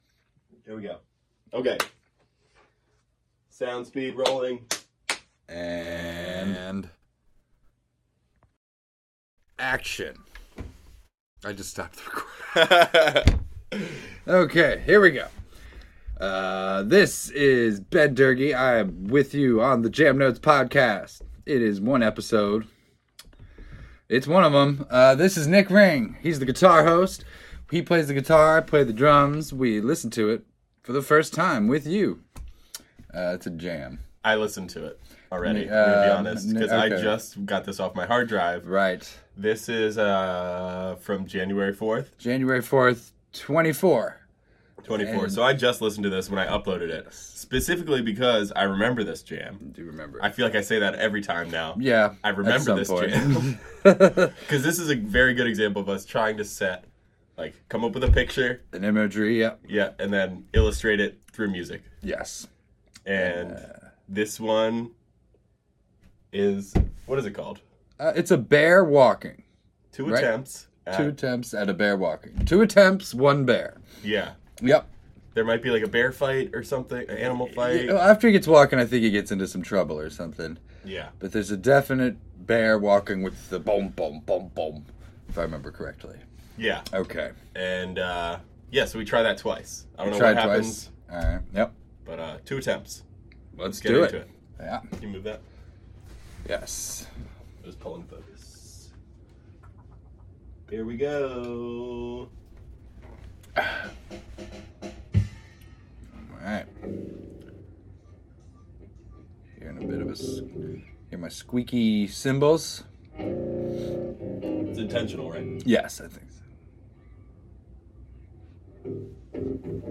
Listening and reacting to a jam from January 2024, imagining a bear waking up from hibernation and getting into some trouble… Also discussing potential drummers for Primus.
It’s a crunchy primal piece supposedly about a bear taking a piss.